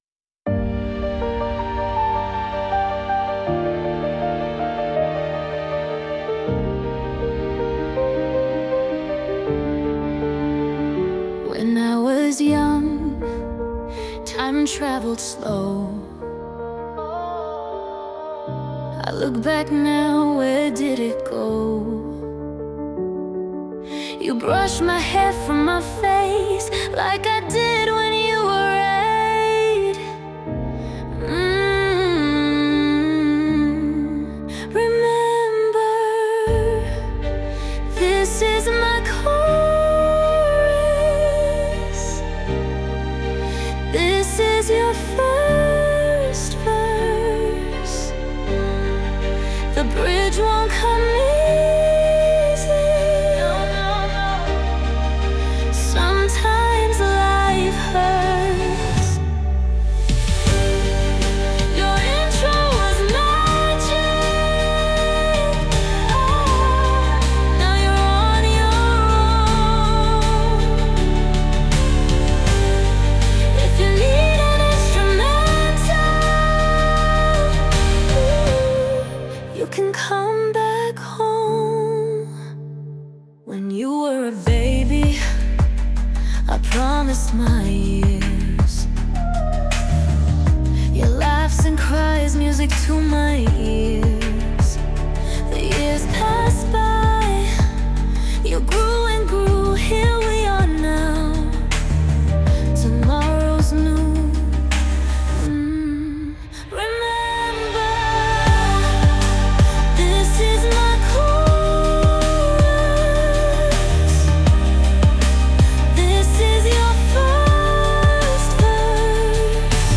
"This Is My Chorus" (pop)